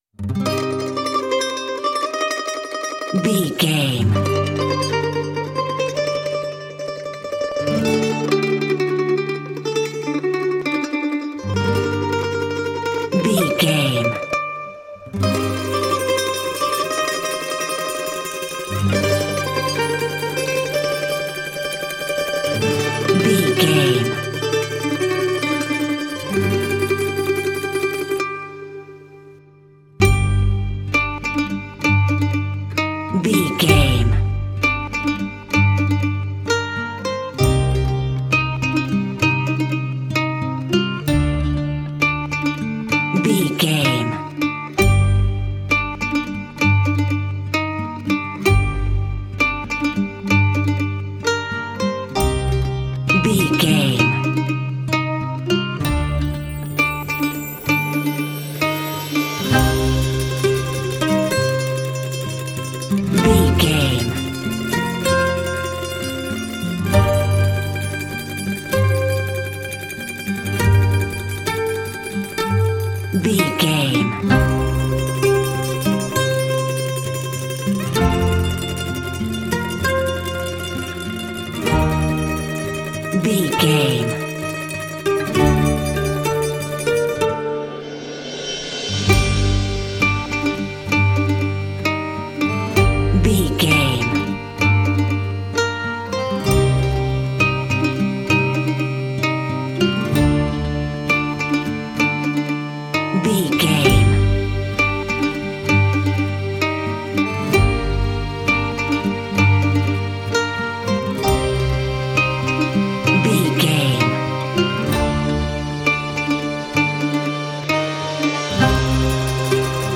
Aeolian/Minor
Slow
dreamy
calm
melancholic
romantic
accordion
acoustic guitar